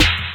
Snare (Good Life).wav